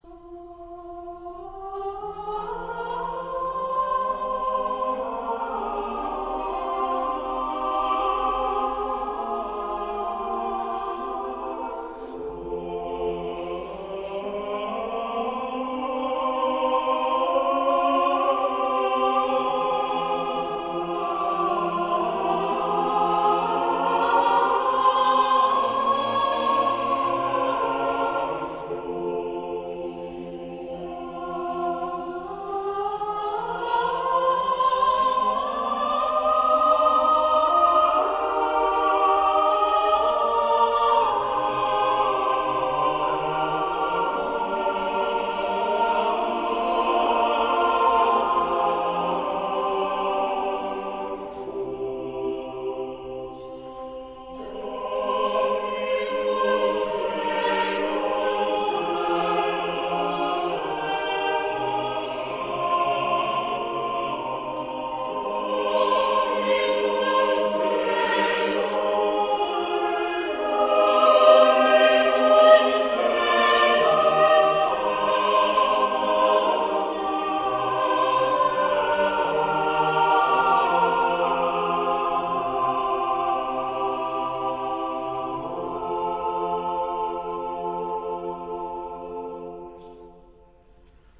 Here are some samples of great choral music, mostly own recordings made during concerts of the choirs named on this site.